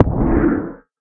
elder_hit3.ogg